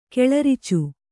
♪ keḷaricu